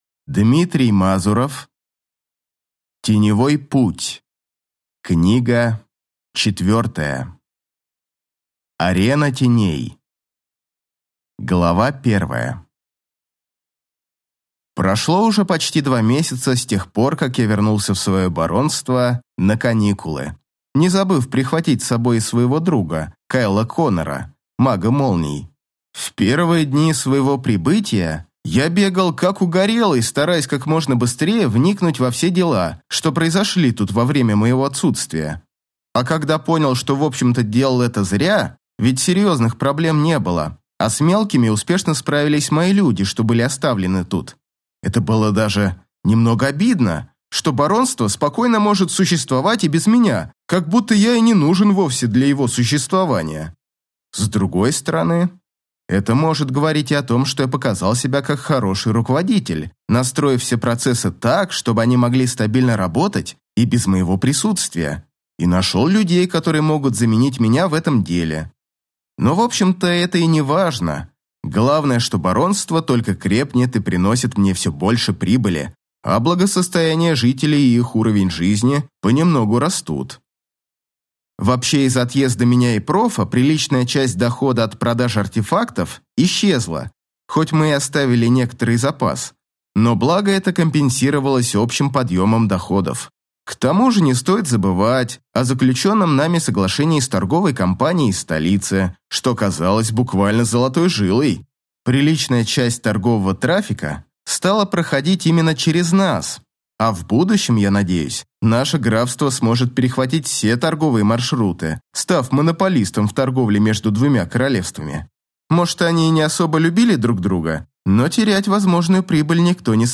Аудиокнига Арена теней | Библиотека аудиокниг